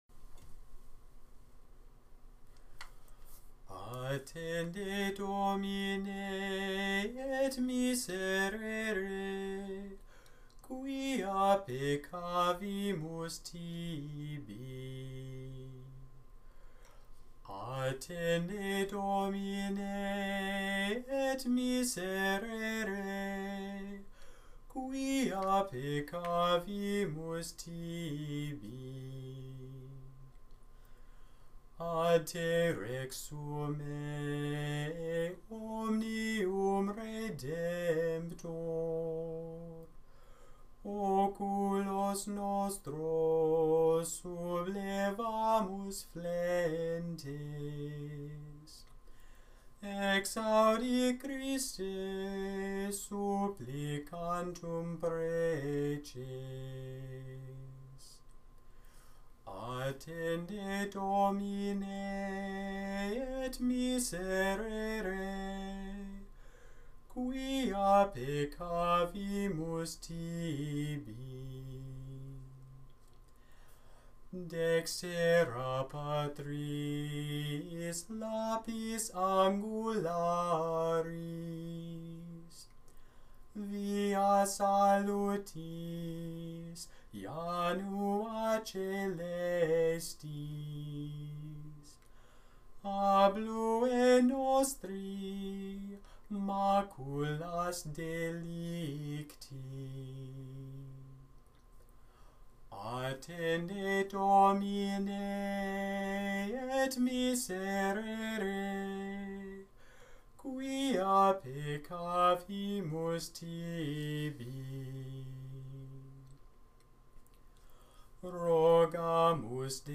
Gregorian, Catholic Chant Attende Domine